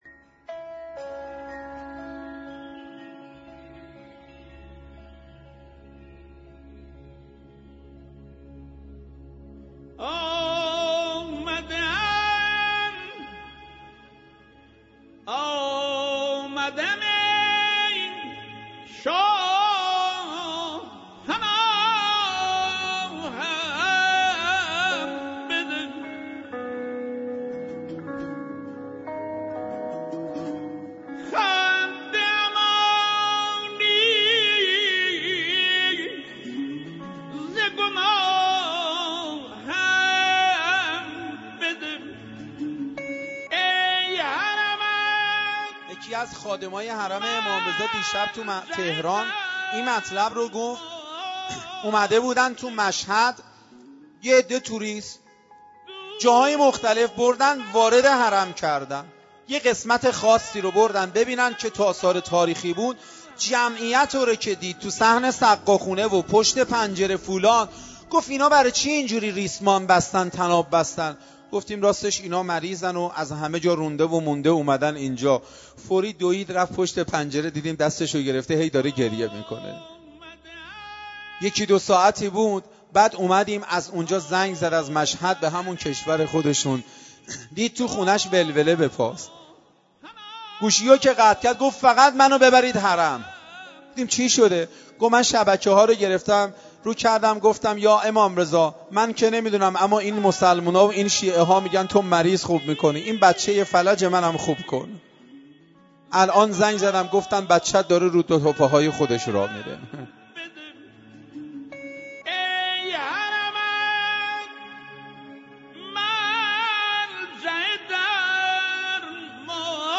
آخرین خبر/ سخنرانی بسیار شنیدنی درباره آثار زیارت امام رضا(ع) را بشنوید.